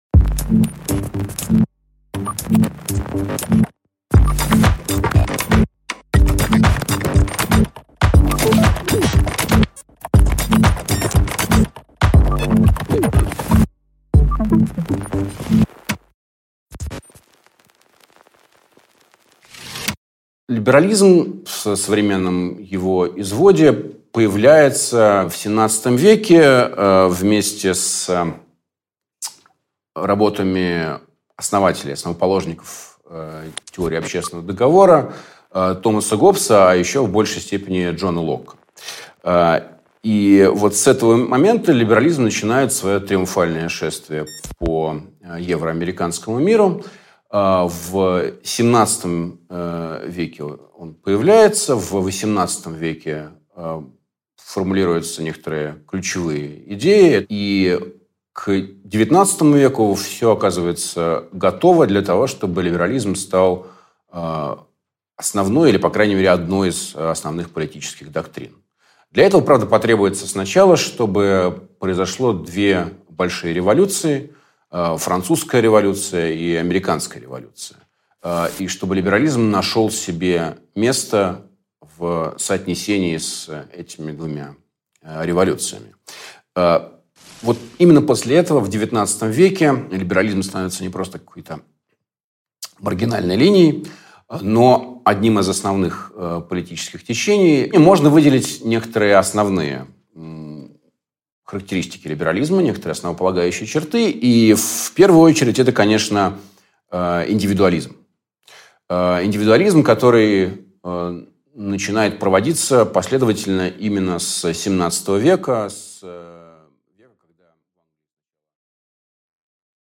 Аудиокнига Перезагрузка политики | Библиотека аудиокниг